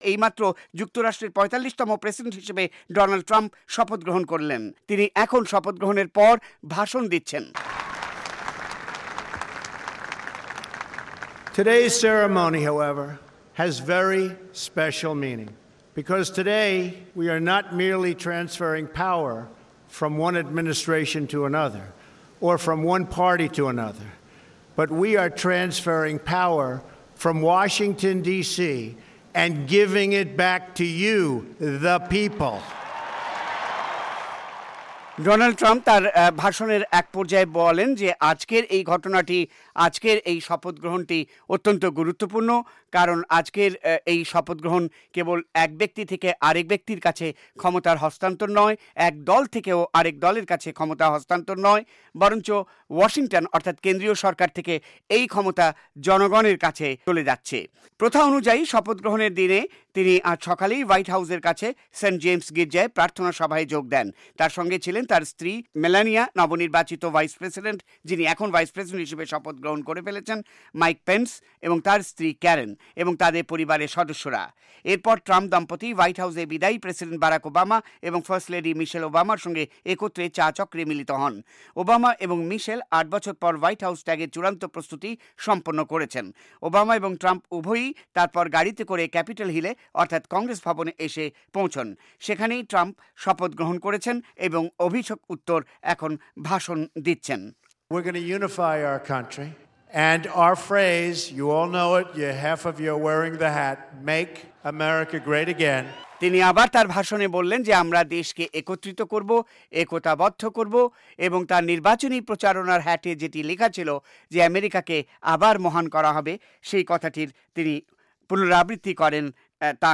ডনাল্ড ট্রাম্পের শপথ গ্রহণ উত্তর ভাষণের অংশ বিশেষসহ সরাসরি খবর দিচ্ছেন